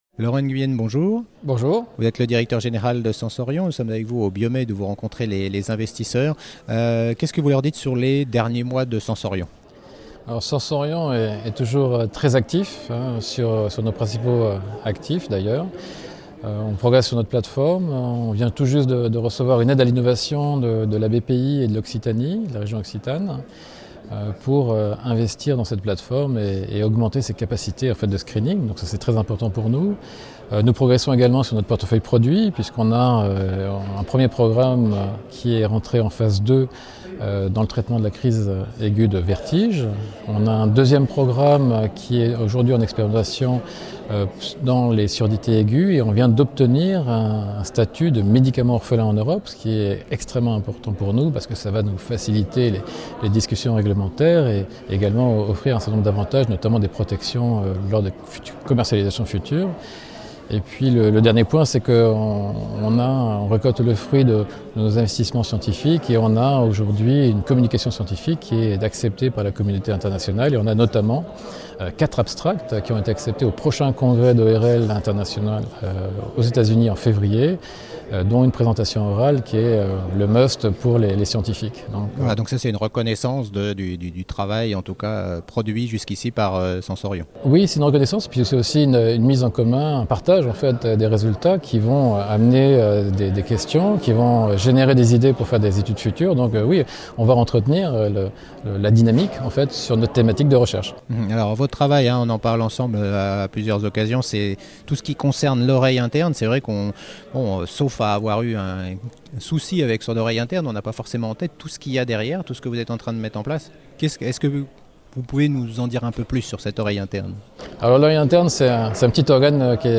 La Web Tv au Biomed Event 2017 organisé par CF&B Com